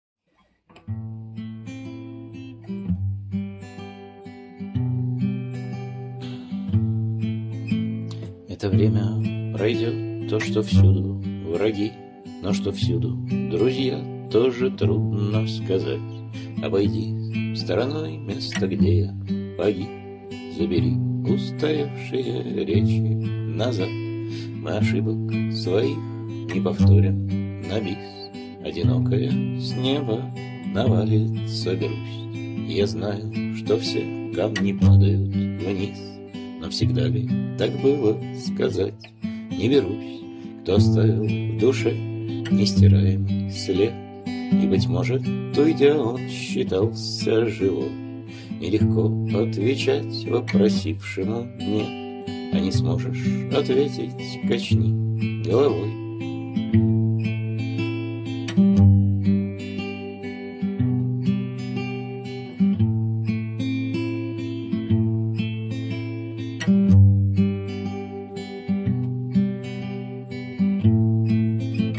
askold.mp3 (1125k) Посмертная ария Асколда